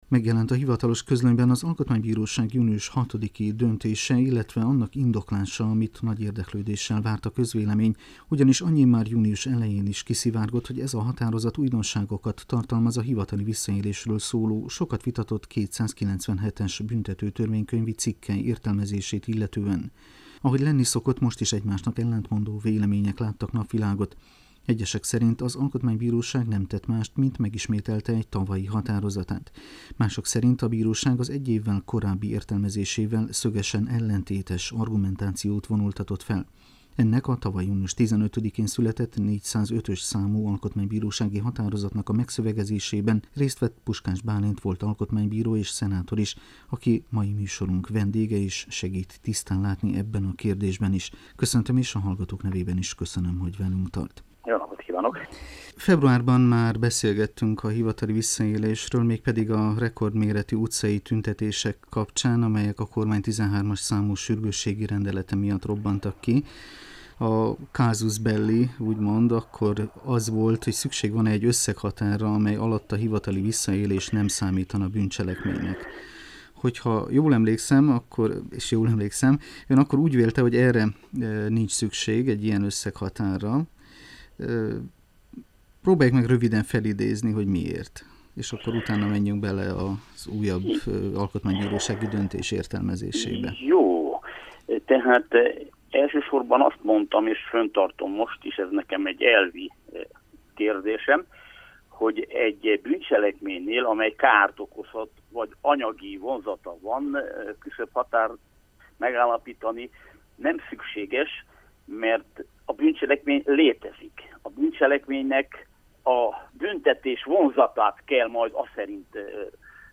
A meglepőnek tűnő kijelentést az a Puskás Bálint tette a Bukaresti Rádióban, aki egy éve még az Alkotmánybíróság tagja volt, és kilenc éven át belülről látta az intézmény működését, tanúja volt számtalan precedens értékű döntés megszületésének. A volt alkotmánybírót annak kapcsán kértük mikrofon elé, hogy megjelent a Hivatalos Közlönyben az alkotmánybíróság június 6-i döntése, amely – Puskás Bálint szerint is – újraértelmezi a hivatali visszaélésről szóló egy évvel korábbi alkotmánybírósági határozatot.